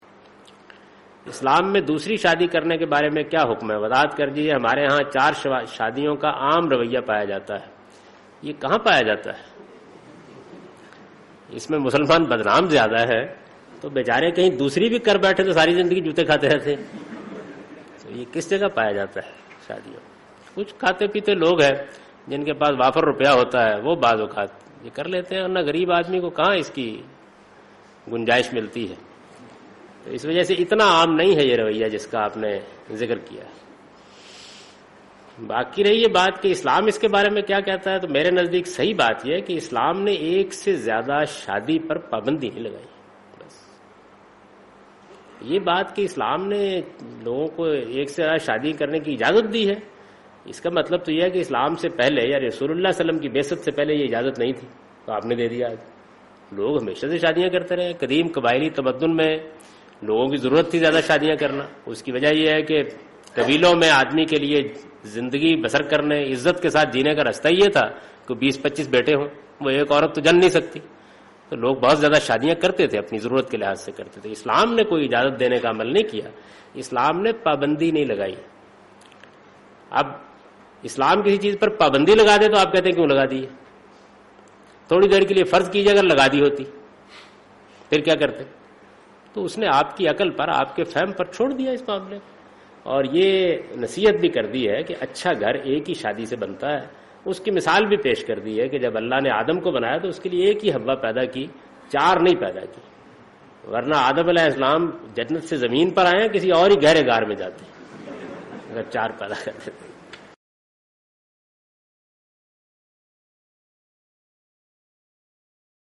Category: English Subtitled / Questions_Answers /
Javed Ahmad Ghamidi responds to the question 'Islamic view of polygamy'?